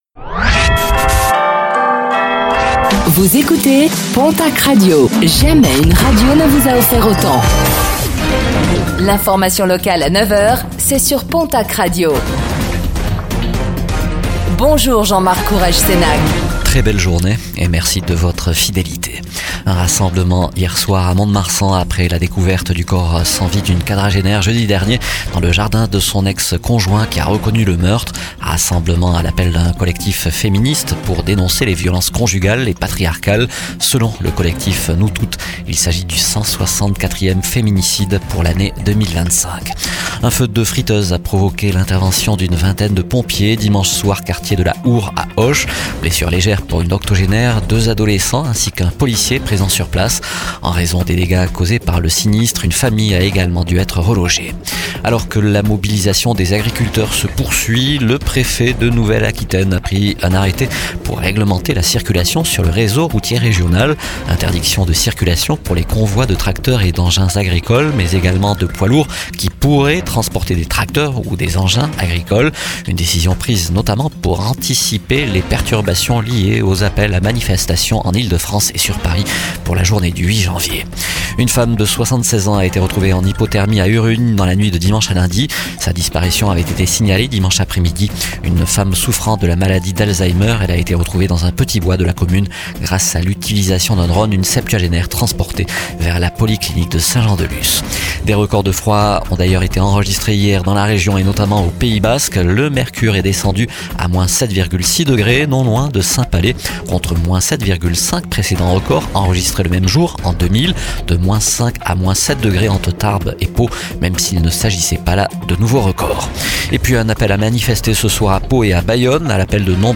Réécoutez le flash d'information locale de ce mardi 06 janvier 2026